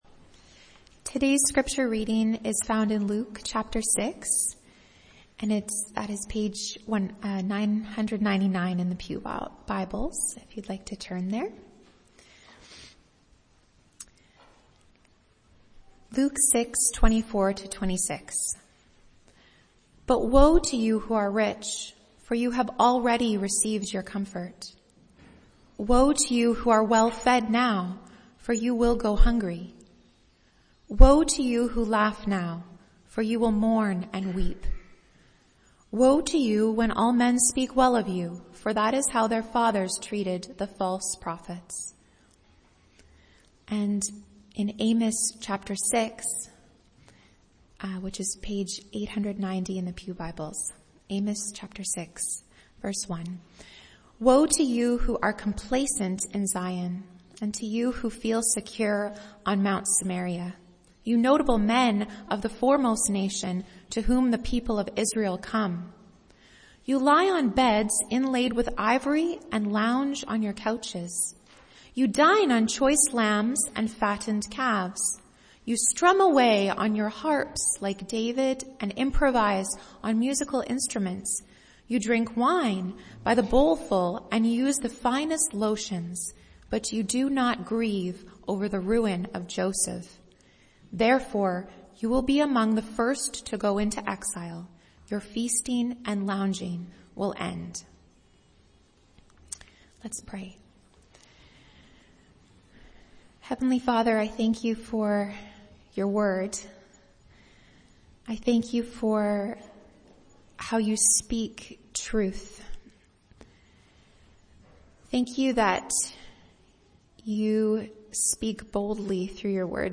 MP3 File Size: 16.3 MB Listen to Sermon: Download/Play Sermon MP3